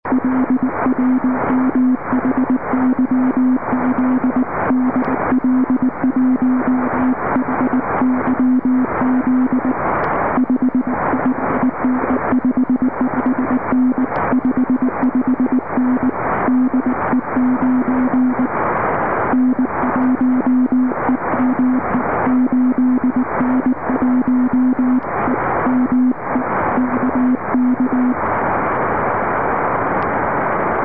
U 8N1EME se jedná o parabolu 32m průměr a 500W.